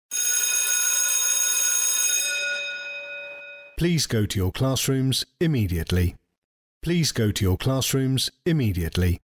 DownloadsSample Message (School Bell).mp3
School.mp3